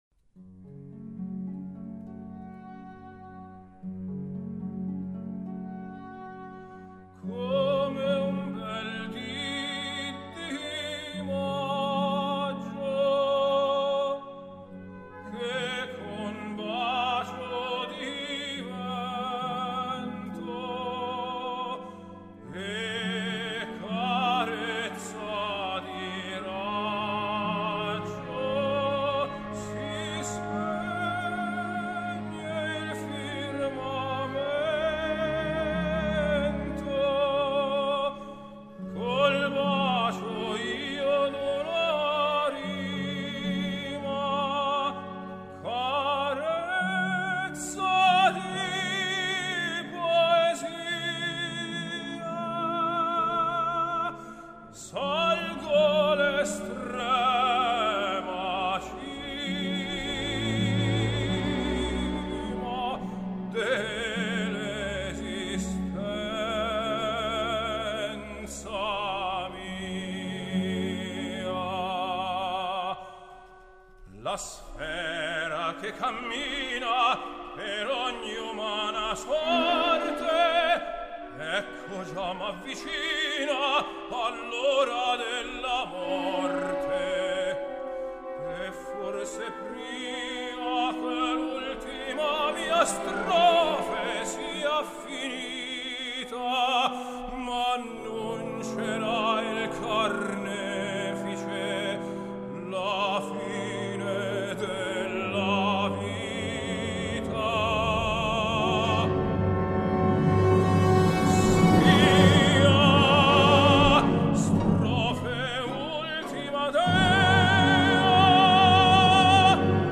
类别：古典音乐